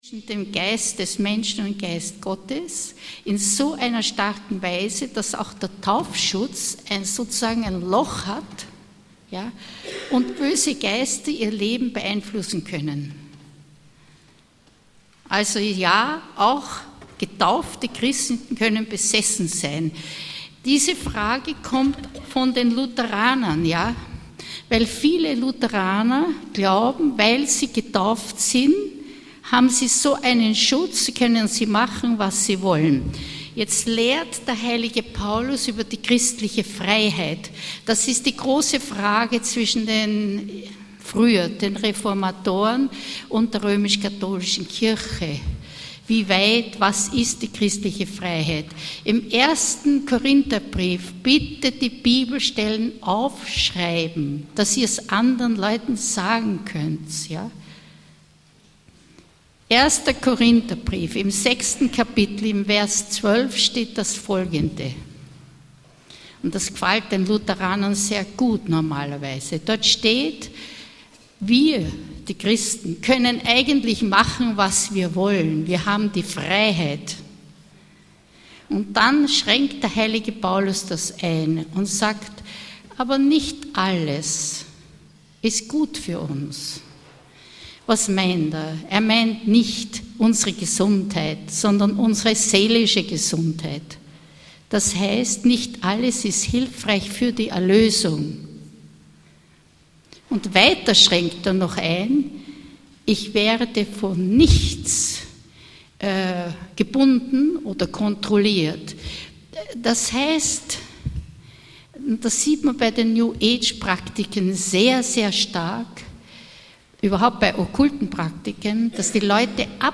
Exerzitien für Innere Heilung in Wien (Marianneum)